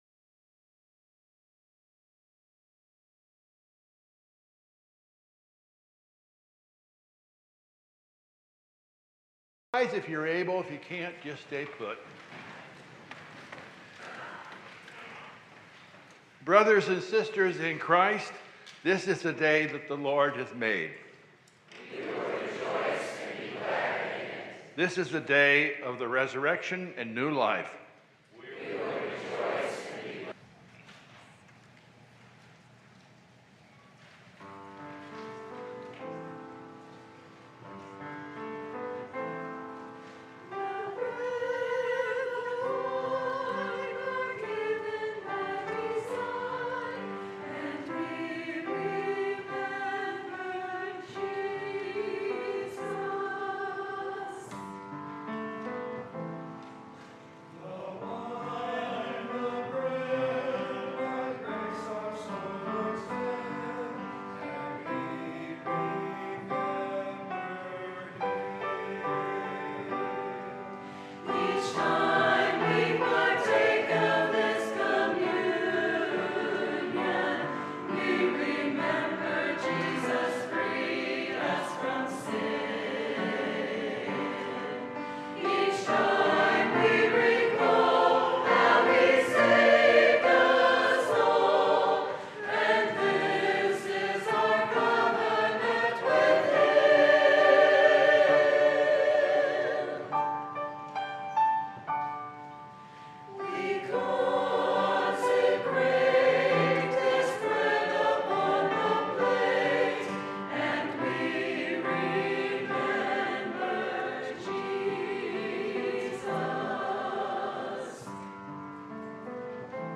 A ‘Time of Hope’ is broadcast each Sunday morning at 8:15 AM on local radio station KRLL (1420 AM).